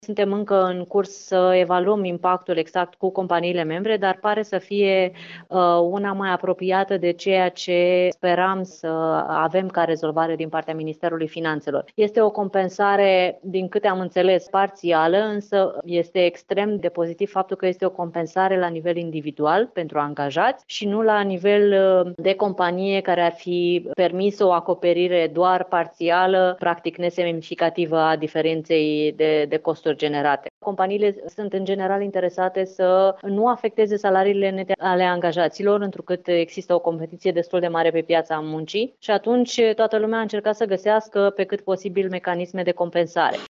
Într-o declaraţie acordată colegului nostru